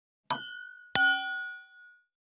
316,ガラスのグラス,ウイスキー,コップ,食器,テーブル,
効果音